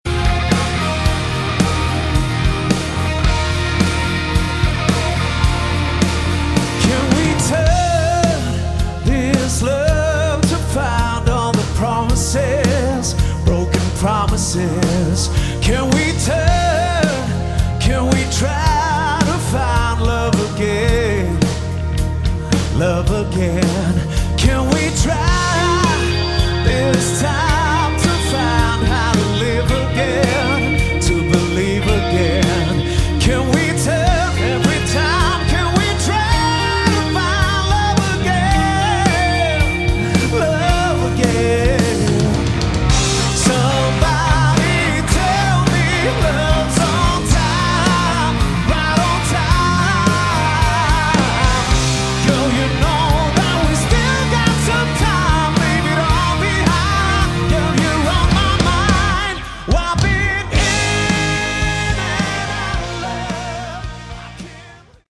Category: Melodic Rock
lead vocals
guitar
keyboards
drums
bass